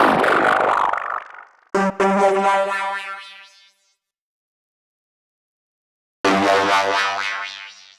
Session 08 - SFX Synth.wav